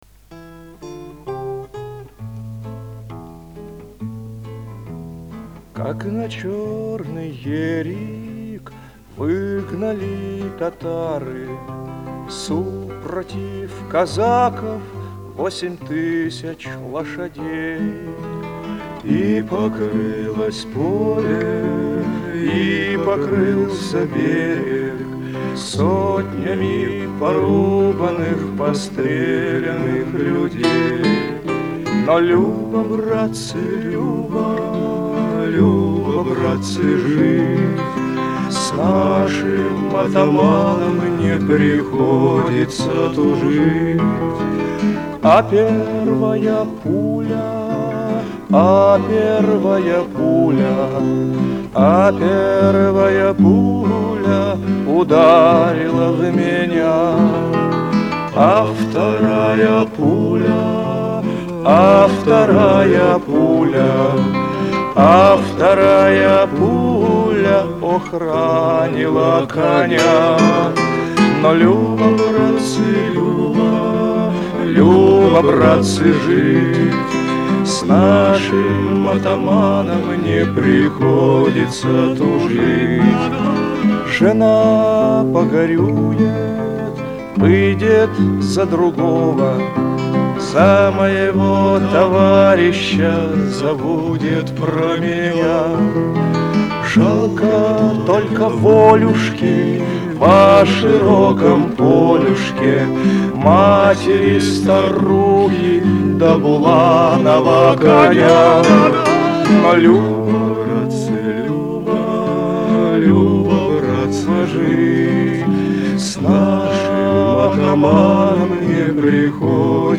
Это песня в исполнении Александра Хочинского, Юрия Каморного и Александра Кавалерова (? - голоса Хочинского и Каморного прослушиваются явно, а Кавалерова-взрослого я на слух не определю).